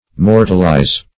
Search Result for " mortalize" : The Collaborative International Dictionary of English v.0.48: Mortalize \Mor"tal*ize\, v. t. [imp.